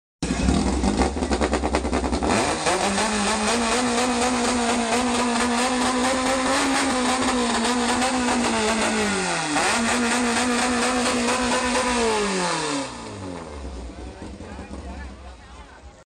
4age 20v Raw Sound 🔥 sound effects free download